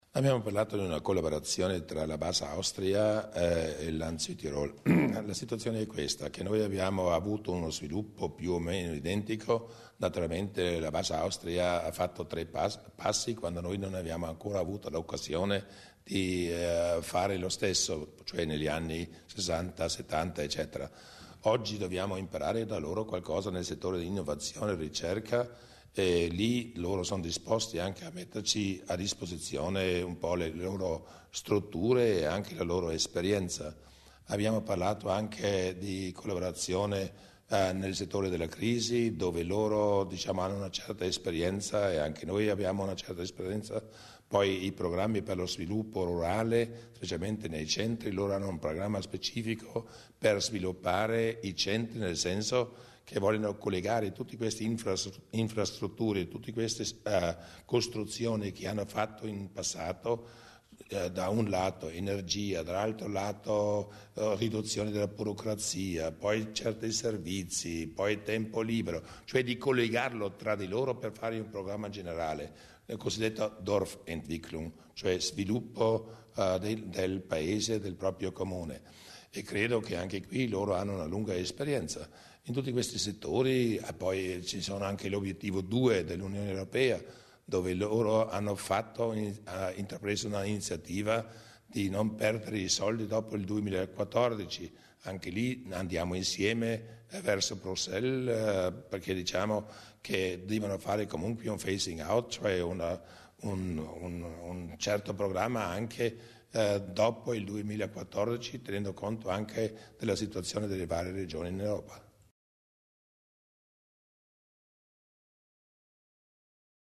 Il Presidente Durnwalder sui temi trattati